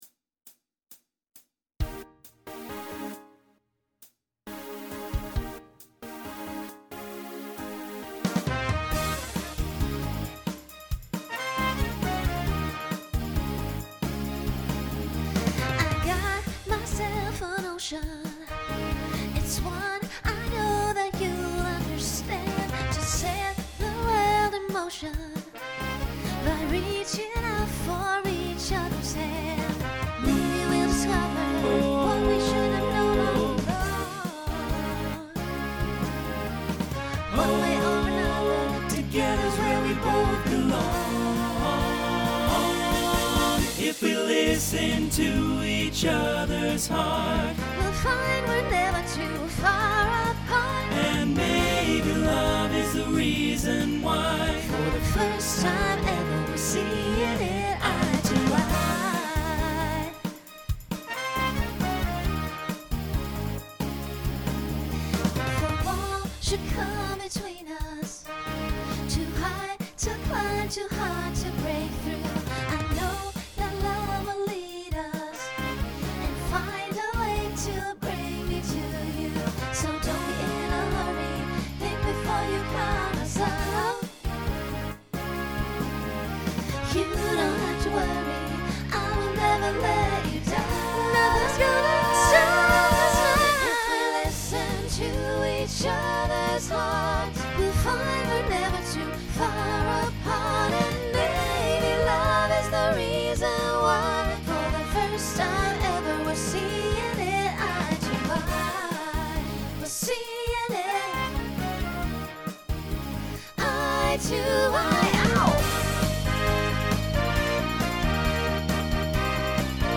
Genre Broadway/Film , Pop/Dance
Transition Voicing Mixed